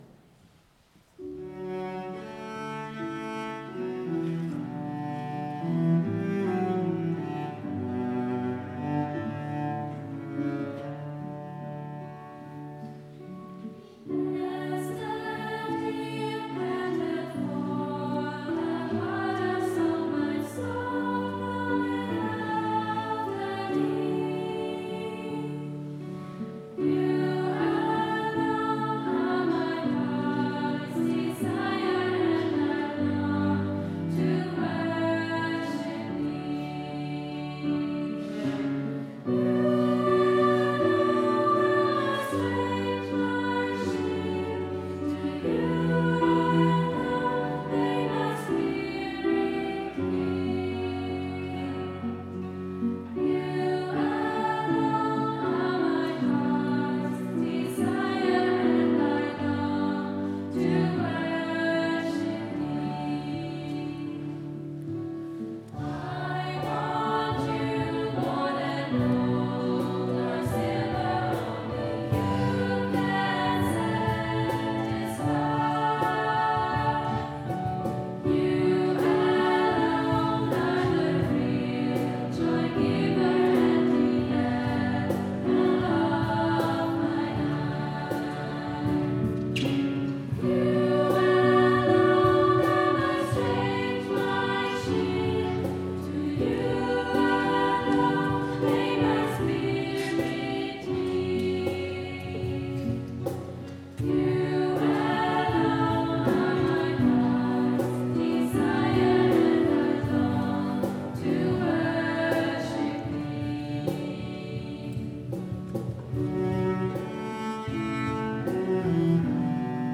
Nach längerer Pause gab es mit einem besonders stimmungsvollen Gottesdienst einen Neustart.
Unser Jugenchor Chorisma gestaltete den Pfarrgottesdienst am 1. Adventsonntag